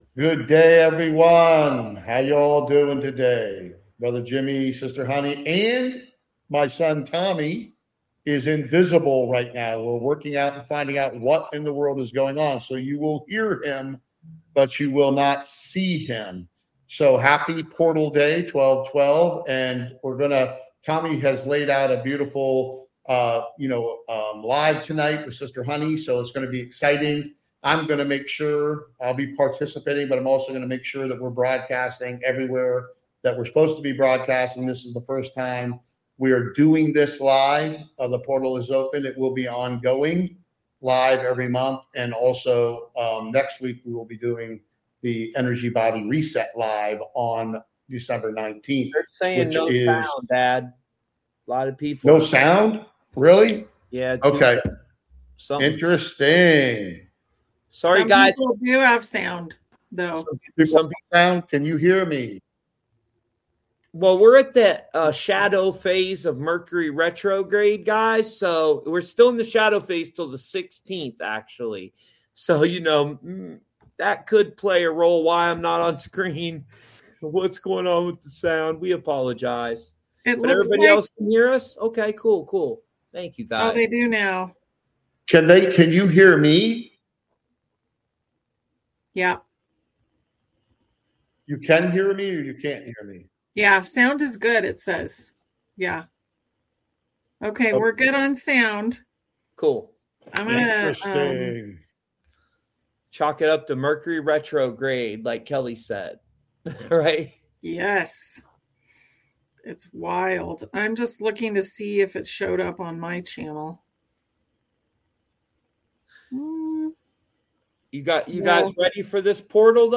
Join Portal Day 1212, where hosts explore astrology, numerology, and wellness.